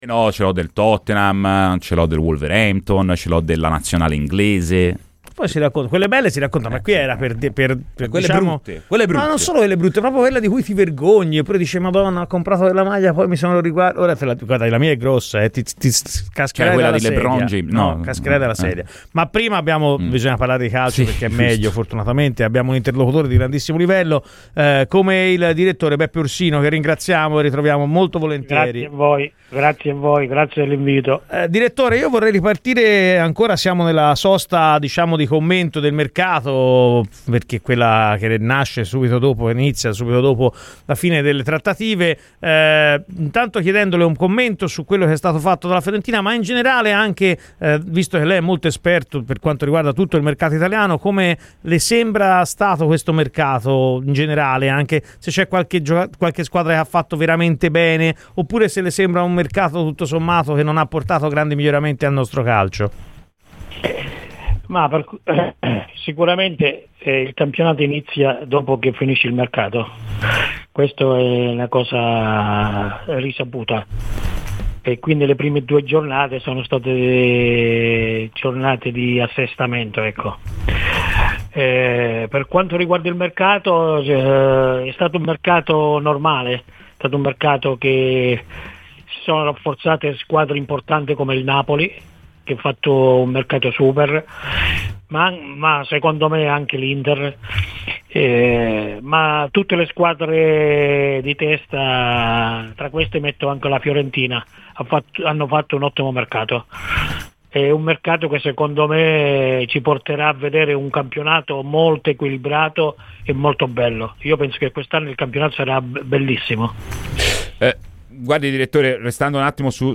Radio FirenzeViola
Ascolta il podcast per l'intervento integrale